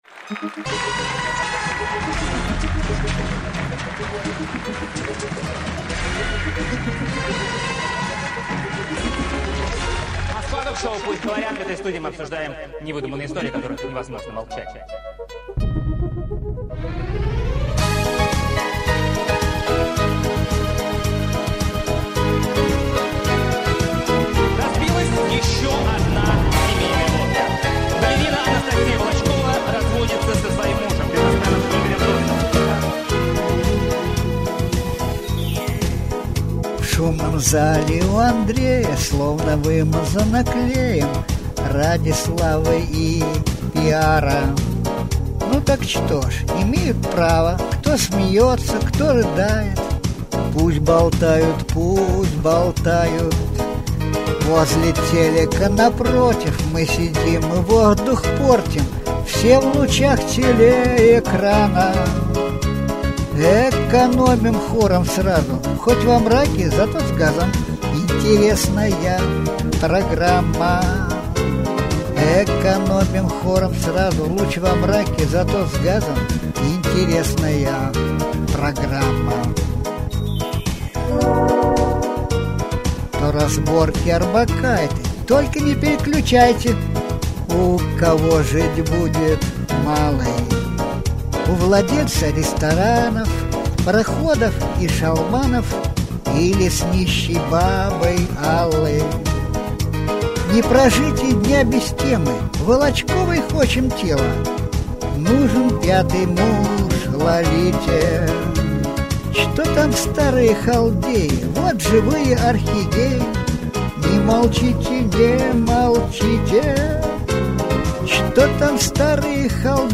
Музыкальная пародия